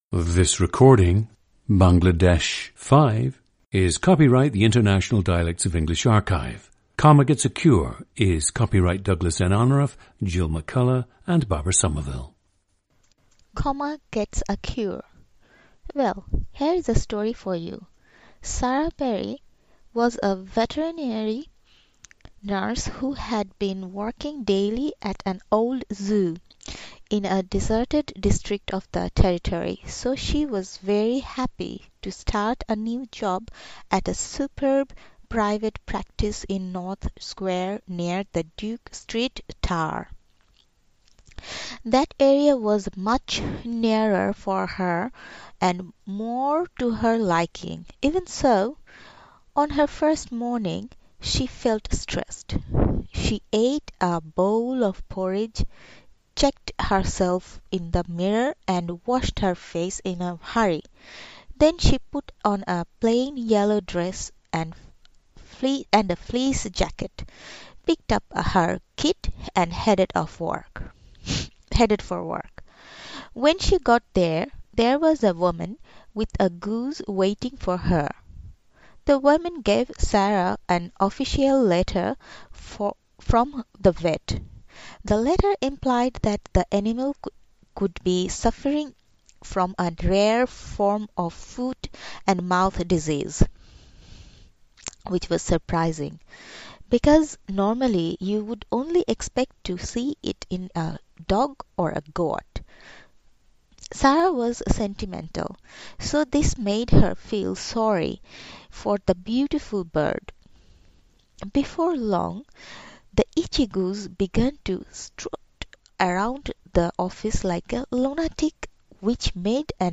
PLACE OF BIRTH: Dhaka, Bangladesh
GENDER: female
The subject until recently spoke English without stressing syllables in multi-syllabic words and also without using the different suprasegmental features of English speech.
She also said that she did not know of the English consonant sound /ʒ/ and pronounced words containing this sound with /z/.
• Recordings of accent/dialect speakers from the region you select.
The recordings average four minutes in length and feature both the reading of one of two standard passages, and some unscripted speech.